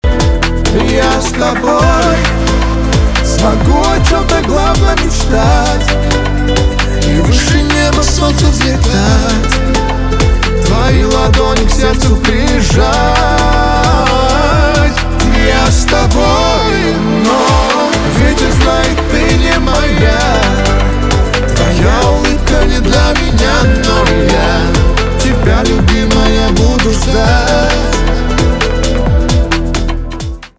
поп
грустные , печальные , чувственные , романтические